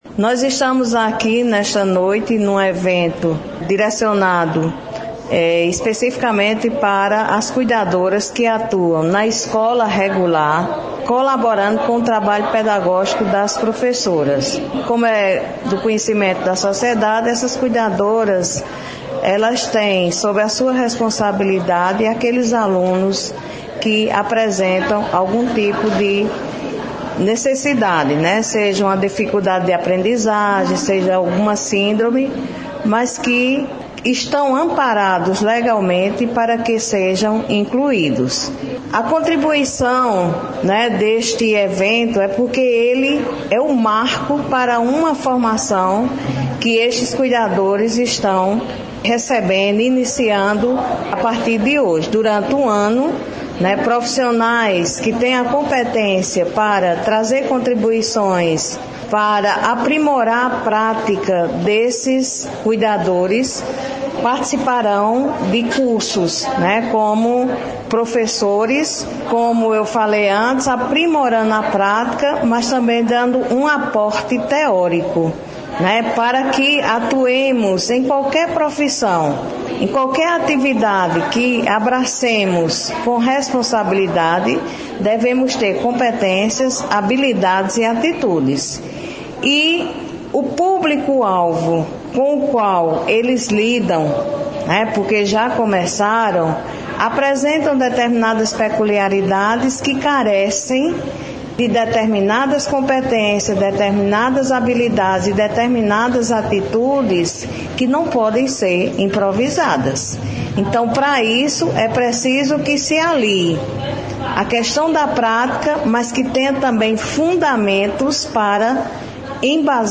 Fala da secretária Municipal de Educação, Alana Candeia –